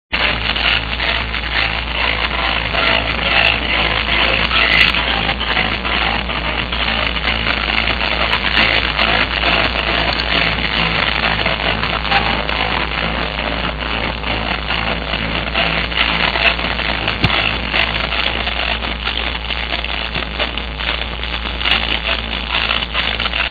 Big Ears Great tune, horrible qulity..
Sorry but I can only hear noise